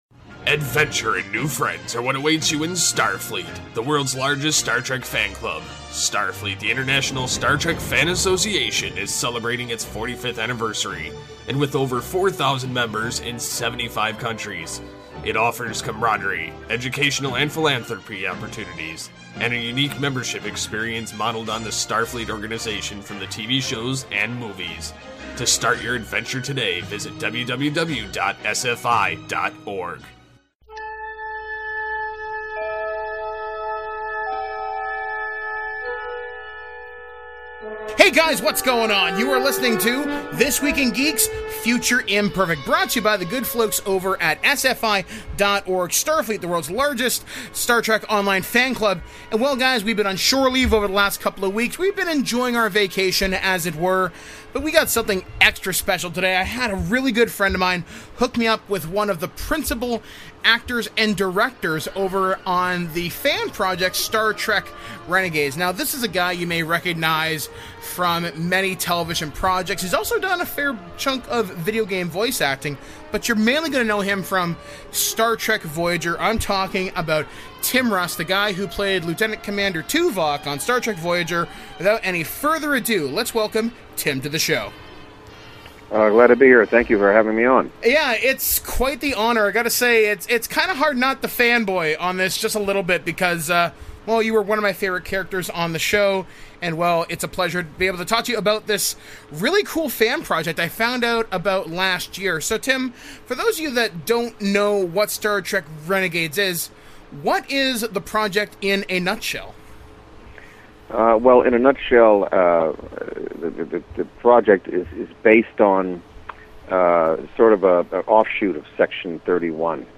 Interviews – Renegade Tim Russ